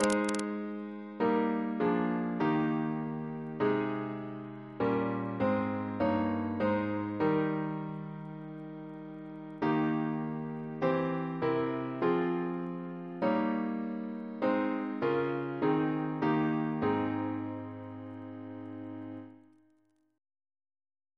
Double chant in A♭ Composer: Richard Woodward, Jr. (1744-1777), Organist of Christ Church Cathedral, Dublin Reference psalters: ACB: 297; ACP: 70; H1982: S264; OCB: 139; PP/SNCB: 157; RSCM: 162